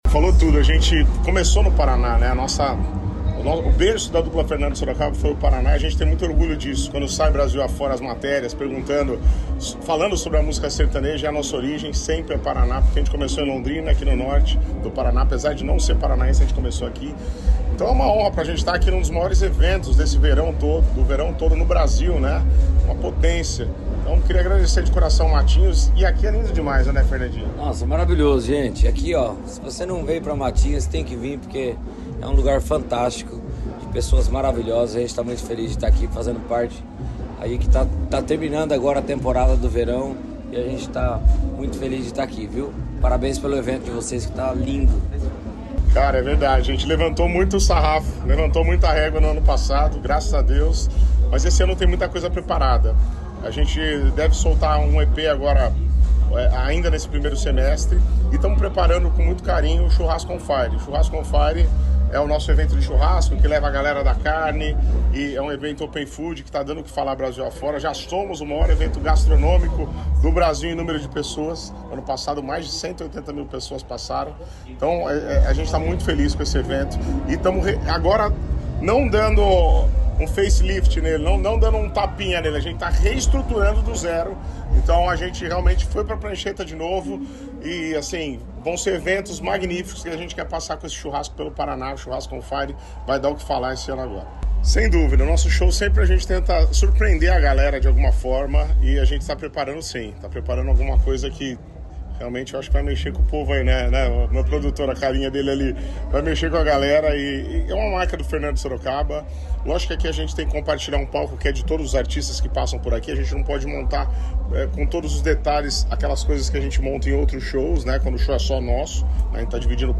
Sonora da dupla Fernando & Sorocaba sobre o show pelo Verão Maior Paraná em Matinhos